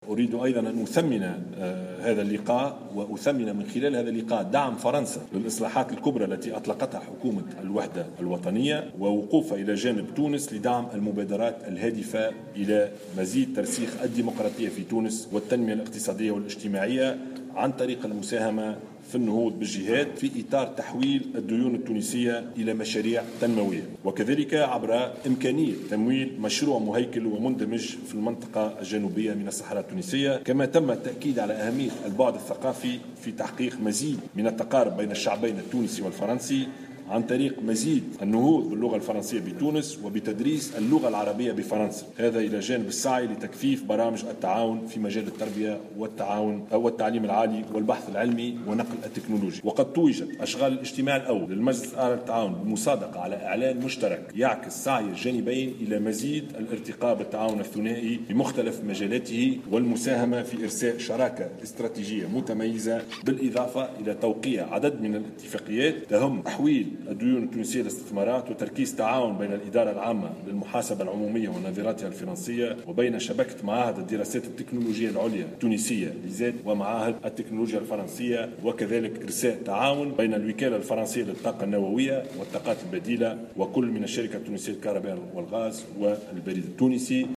قدم رئيس الحكومة يوسف الشاهد في كلمته اثناء اشرافه على افتتاح أشغال الإجتماع الأوّل للمجلس الاعلى للتعاون التونسي الفرنسي تعازيه لضحايا انهيار المبنى بمدينة سوسة مؤكدا فتح تحقيق في الموضوع للوقوف على ملابسات الحادث و تحديد المسوليات.